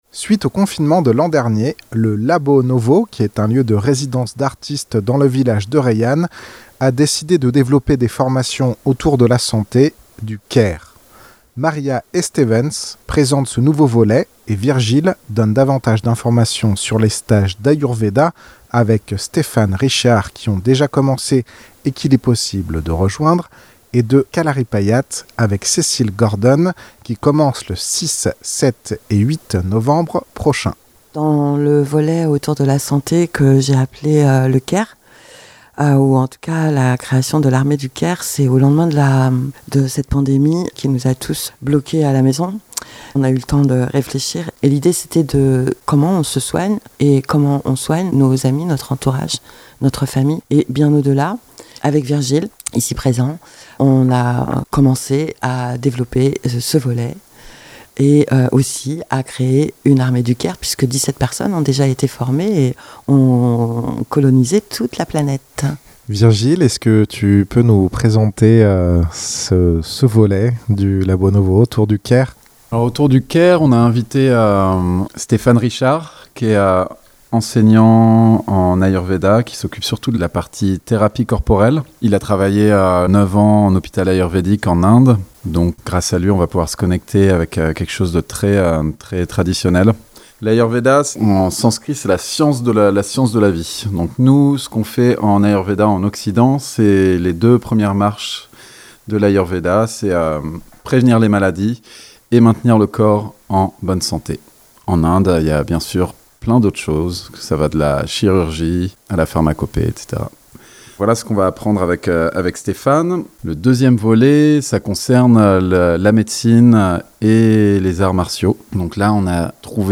Ces stages sont ouvert à toutes et tous sans prérequis. Reportage